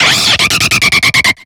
Cri de Bruyverne dans Pokémon X et Y.